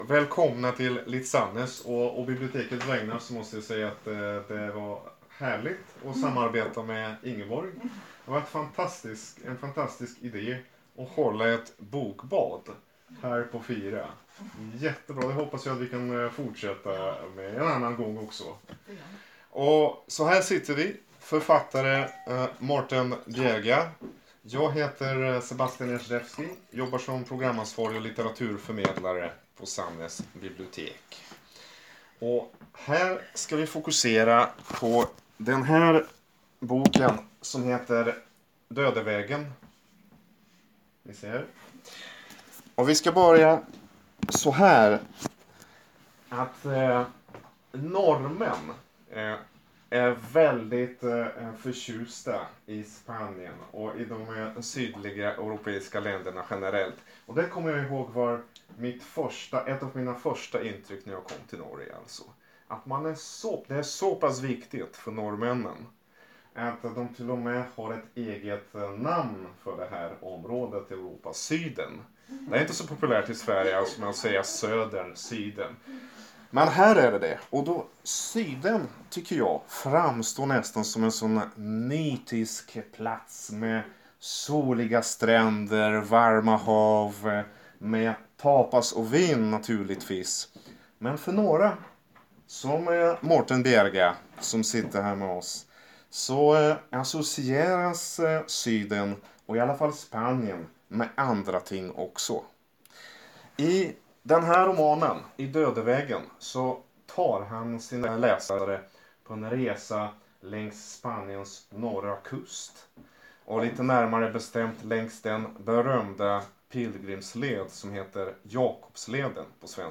Arrangementet var en del av Litt.Sandnes 2019.